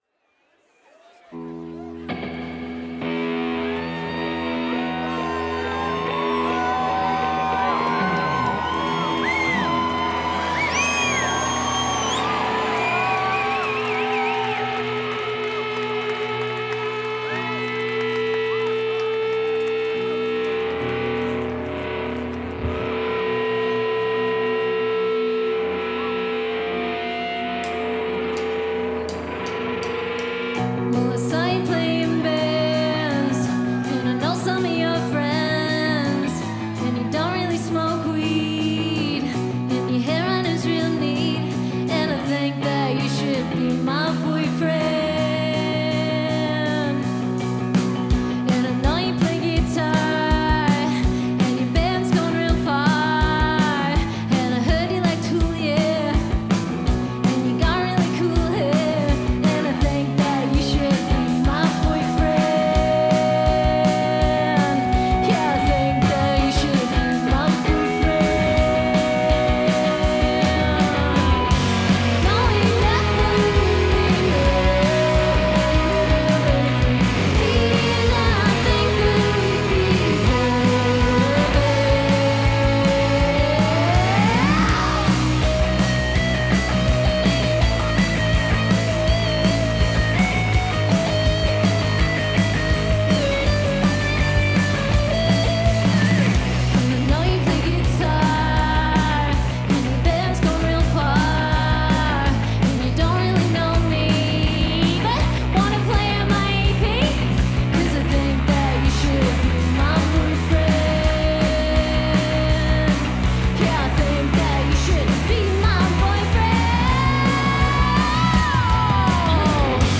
Recorded in concert at The Corner Hotel, Melbourne
scruffy growth and raw honesty.